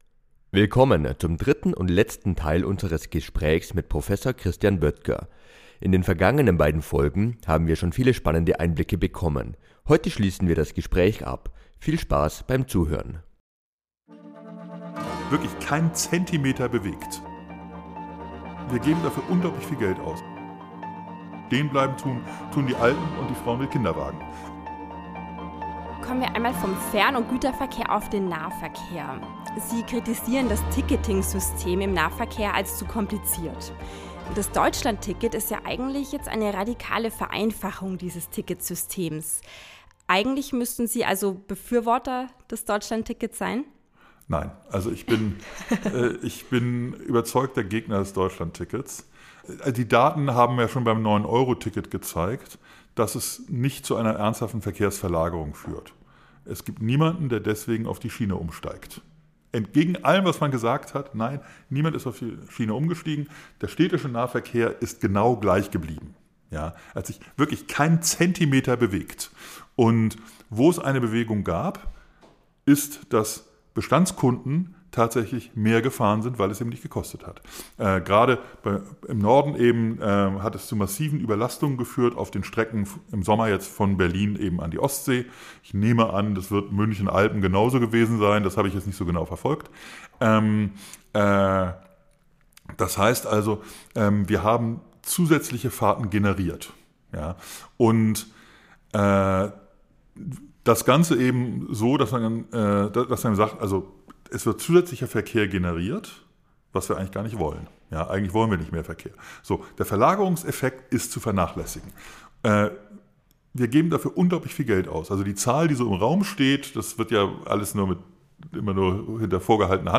Wir haben mit ihm in unserem Büro in Augsburg gesprochen. Ein Gespräch über die Profiteure des Deutschlandtickets, was beim neuen Bundeskanzler vermutlich nicht oben auf seinem Stapel liegt und ob Ulm–Augsburg auf seiner persönlichen Top-5-Liste der wichtigsten Bahnprojekte in Deutschland rangiert.